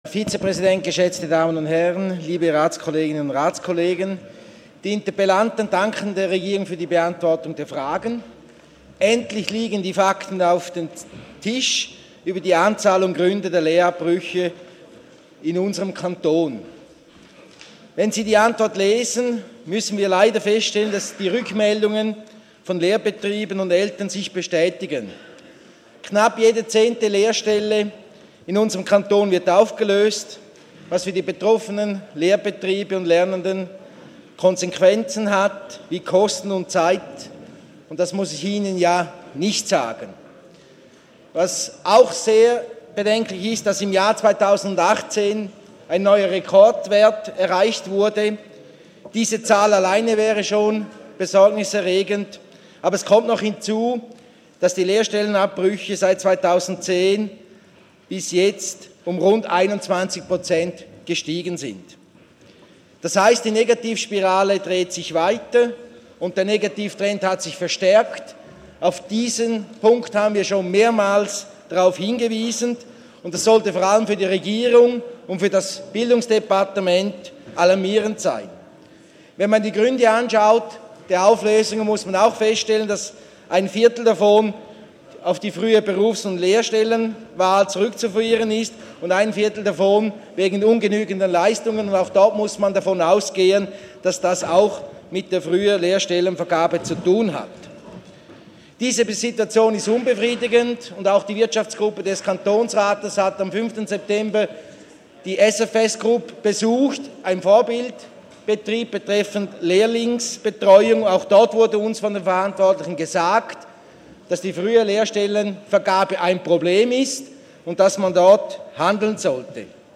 26.11.2019Wortmeldung
Sprecher: Dürr-Widnau
Session des Kantonsrates vom 25. bis 27. November 2019